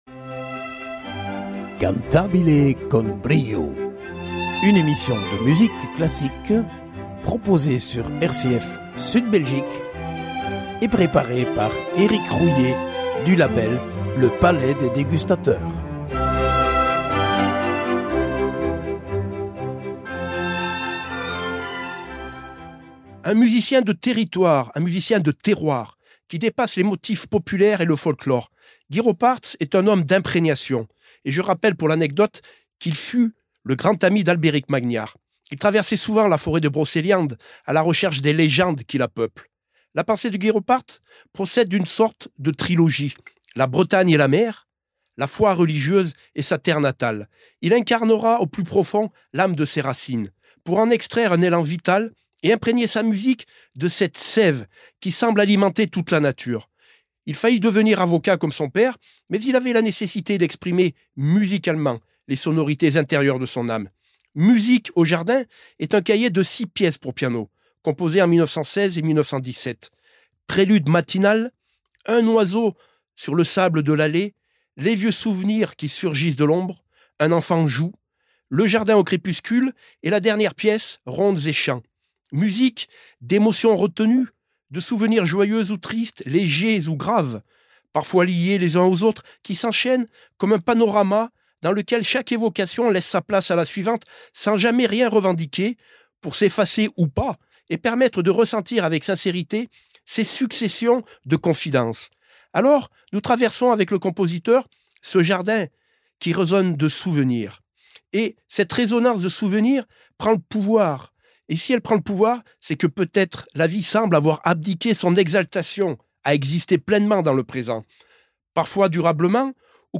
En partenariat avec le ” palais des dégustateurs ” nous vous proposons chaque semaine une émission de musique classique émaillée d’interviews exclusives.